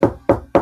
Knocking On Wall Efecto de Sonido Descargar
Knocking On Wall Botón de Sonido